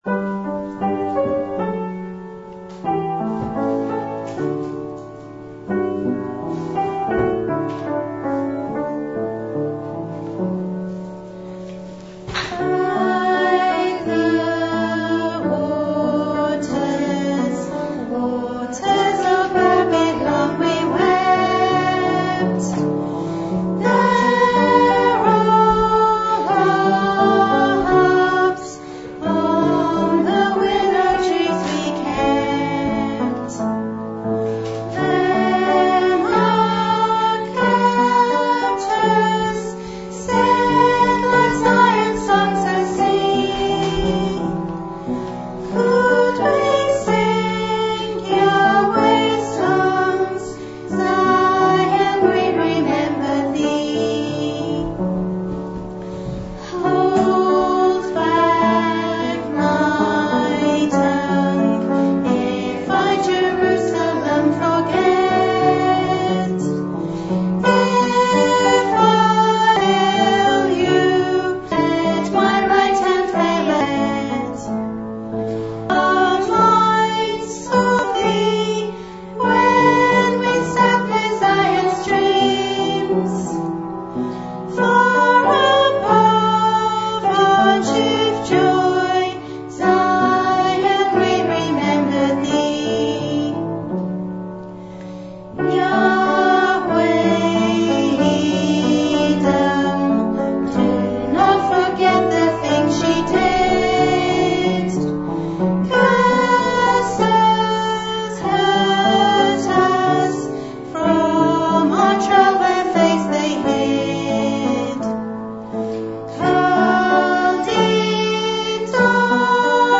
Special Music
by Ladies Choir   Psalm 137 - By the Waters of Babylon
sung Bristol UK UB2 1 Apr 2013